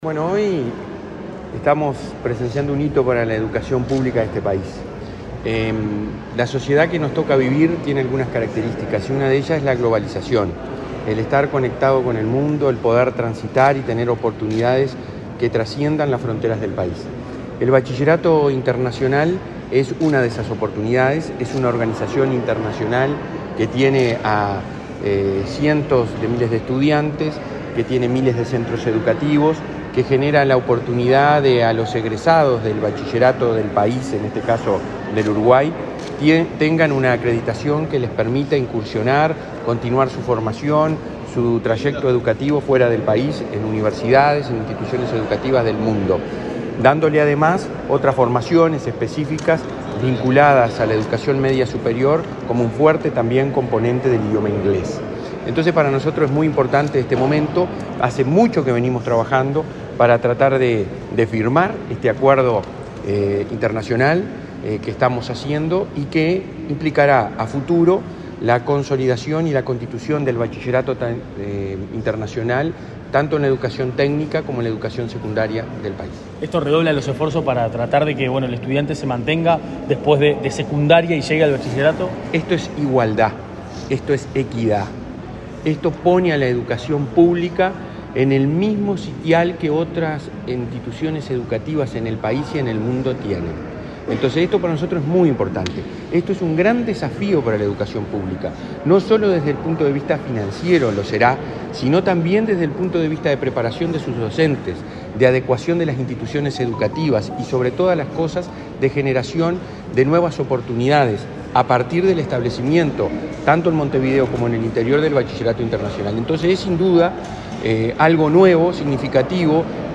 Declaraciones del presidente de la ANEP, Robert Silva
Declaraciones del presidente de la ANEP, Robert Silva 20/07/2023 Compartir Facebook X Copiar enlace WhatsApp LinkedIn Este jueves 20, el presidente de la Administración Nacional de Educación Pública (ANEP), Robert Silva, dialogó con la prensa antes de firmar un convenio marco con autoridades de la Organización del Bachillerato Internacional.